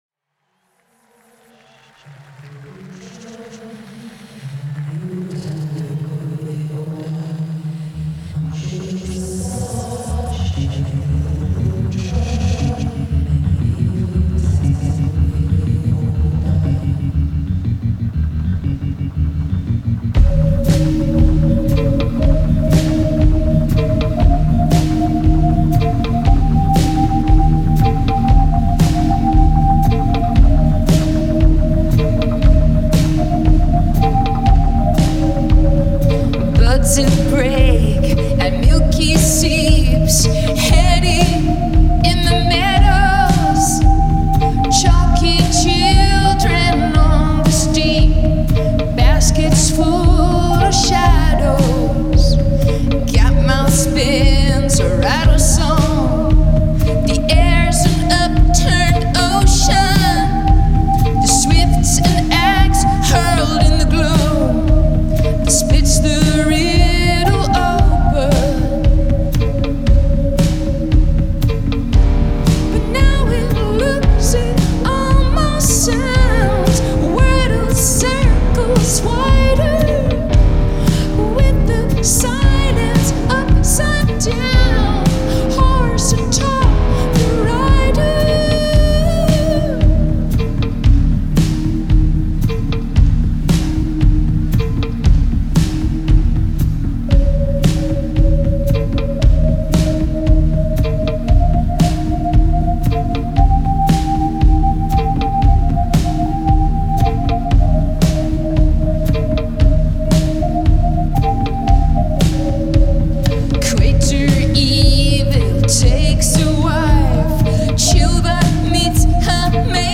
the tonal colors and soundscapes blending with words
it’s the dreamlike quality that gets you. https